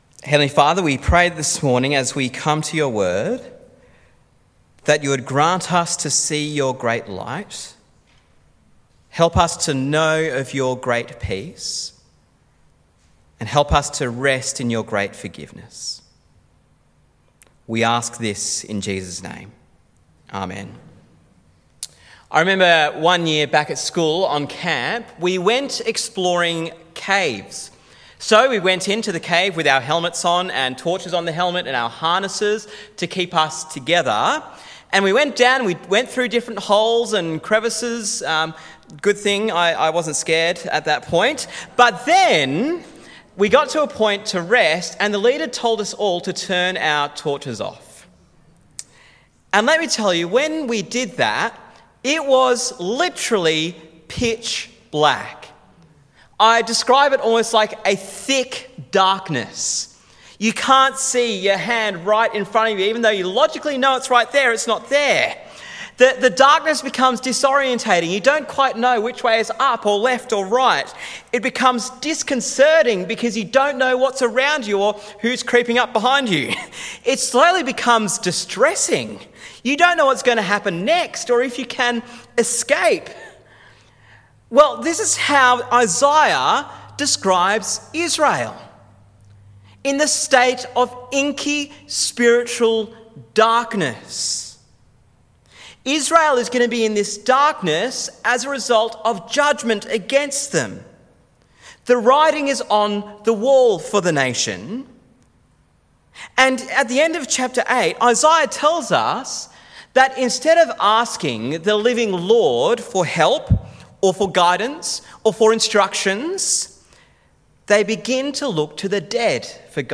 The Great Light This is the Fourth sermon in our series in Isaiah Download Sermon Transcript and Questions Download Series resource Download Sermon Audio See Other Sermons in Series